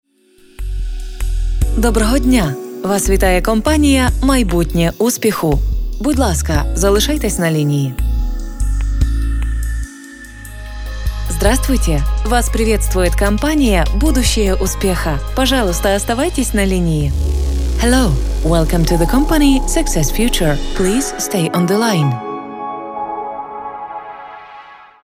Ukrainisch, ukrainian, native speaker, Mutterspachler, vertauerlich, empathic, empathysch
Sprechprobe: Sonstiges (Muttersprache):